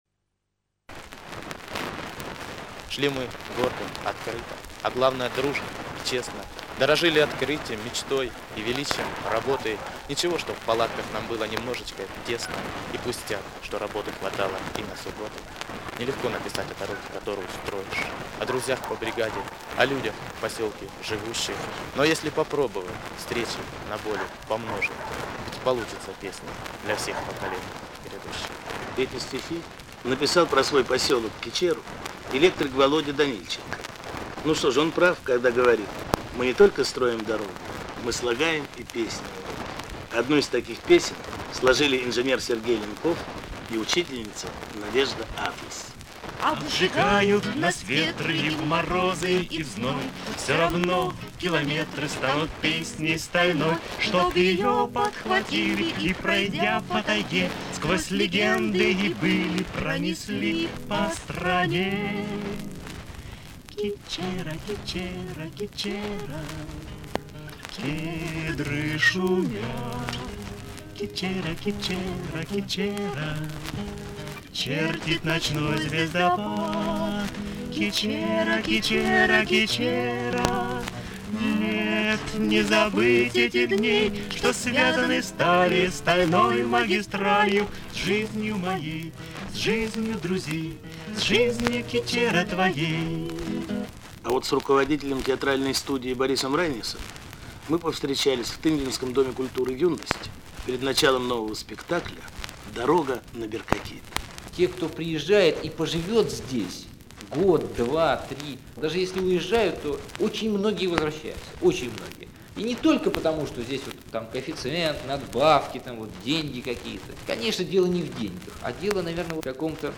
Бамовское ускорение. Говорят клубные работники.